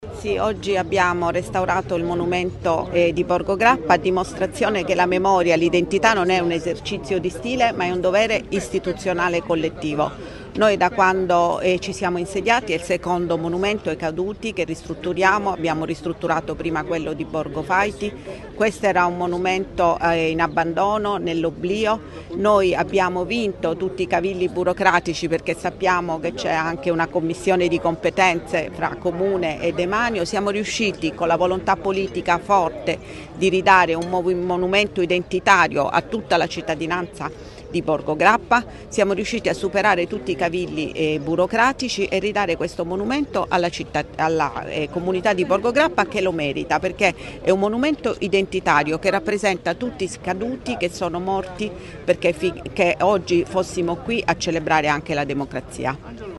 Alle ore 9.30 è prevista la Santa Messa in onore ai Caduti di tutte le guerre presso la Parrocchia San Giuseppe di Borgo Grappa e, alle ore 10.15, la cerimonia di inaugurazione e l’intervento del sindaco Matilde Celentano.